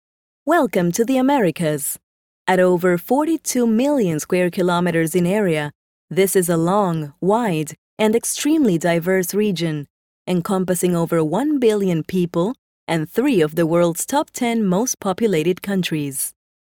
Female
Approachable, Character, Conversational, Corporate, Natural, Warm, Young
ivr.mp3
Microphone: Manley reference Cardioid